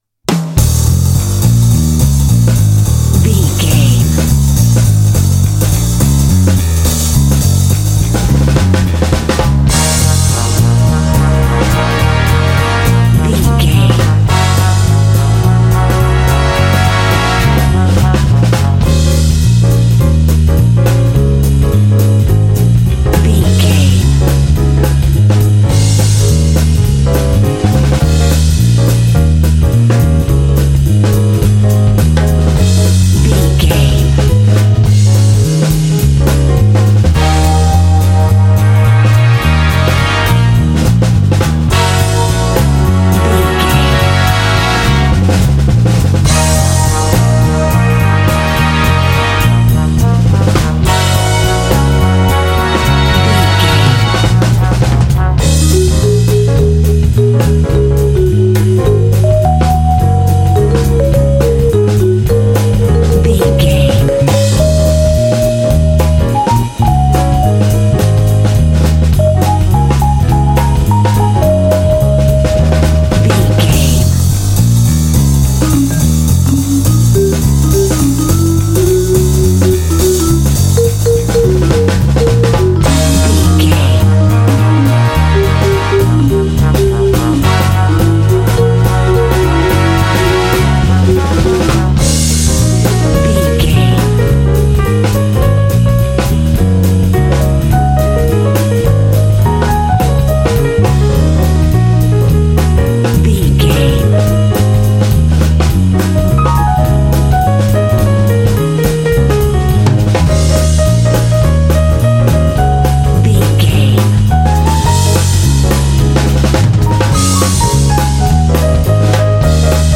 Aeolian/Minor
E♭
driving
energetic
groovy
lively
bass guitar
piano
brass
jazz
big band